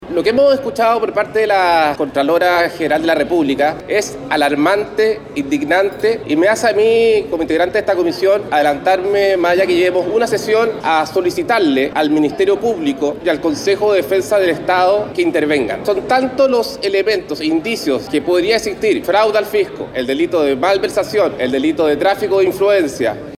En tanto, Andrés Celis (RN), diputado por el distrito N. 7 e integrante de la Comisión de Salud e Investigadora, manifestó su asombro tras escuchar a la contralora General de la República.